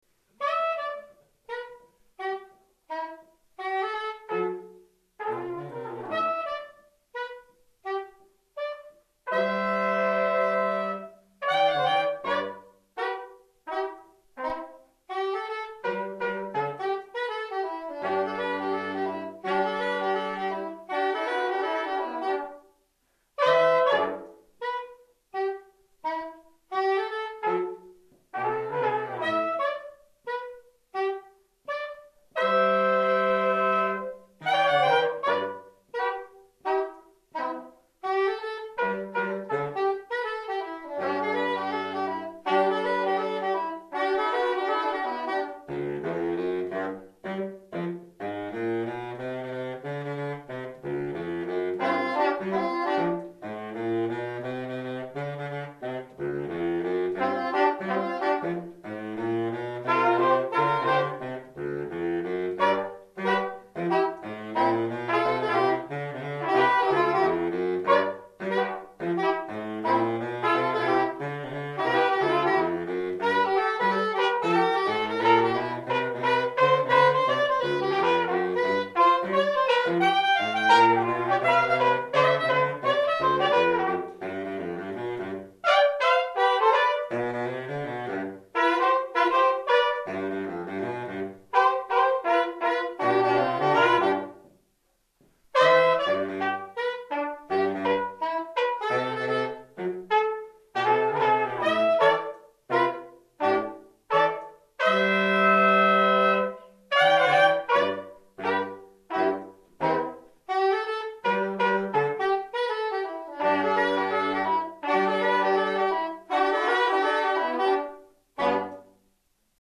ss, bars, tp/flh, !perf